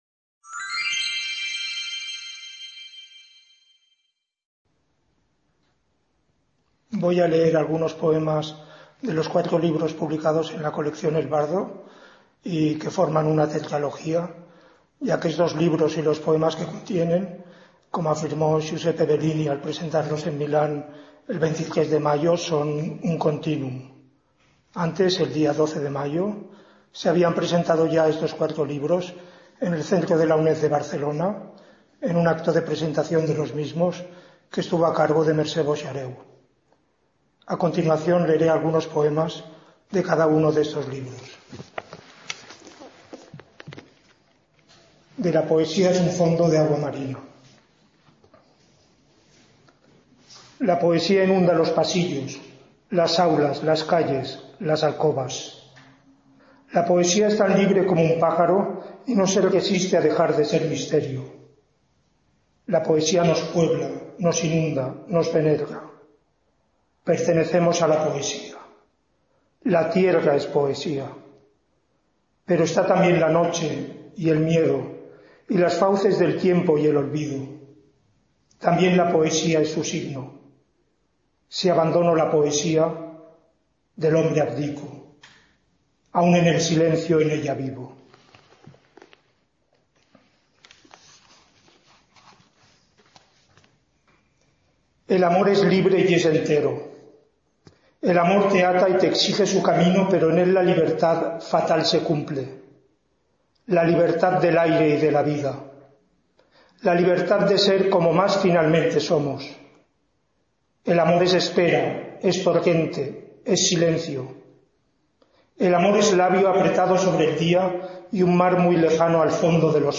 LECTURA DE POEMAS DE CUATRO LIBROS EN LA COLECCIÓN "EL…